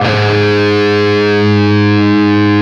LEAD G#1 CUT.wav